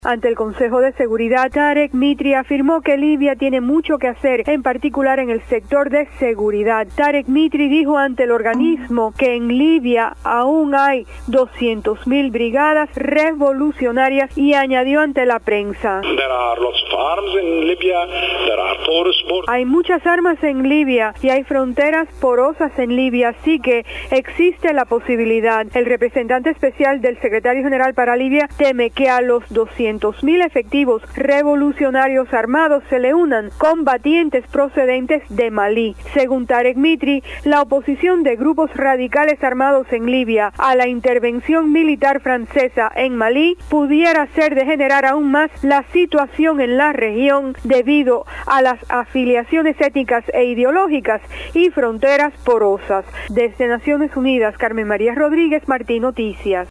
El Jefe de la Misión de Apoyo de las Naciones Unidas en Libia, Tarek Mitri, informó que todavía hay doscientos mil soldados armados de las "brigadas revolucionarias" que derrocaron a Muammar Gadafi en el 2011, quienes todavía no están bajo control del gobierno. Desde la ONU